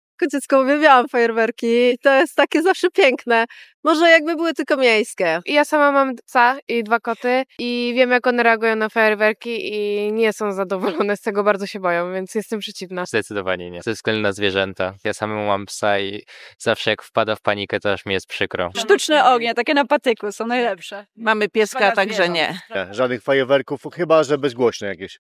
Nasz reporter zapytał mieszkańców Gdańska, czy kupują fajerwerki: